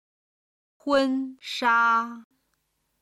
今日の振り返り！中国語発声
婚纱　(hūn shā)　ウェディングドレス